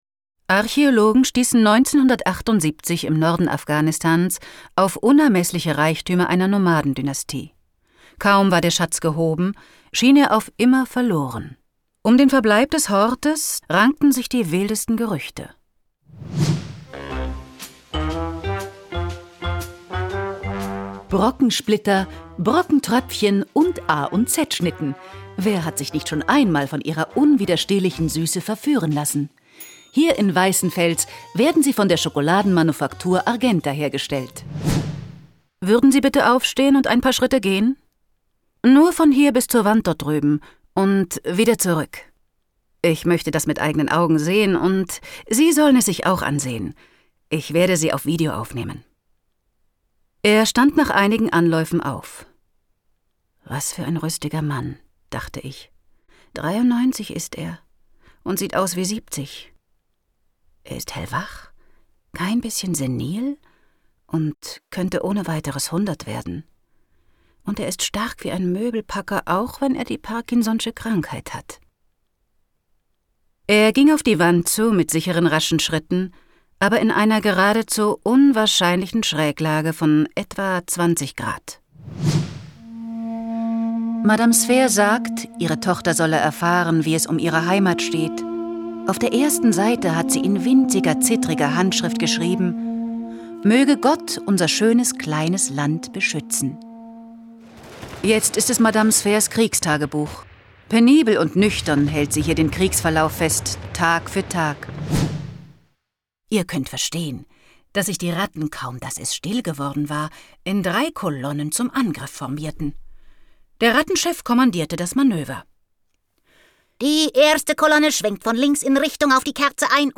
Sprecherin für Hörbuch, Synchron, Dokumentarfilm, Feature
ShowReel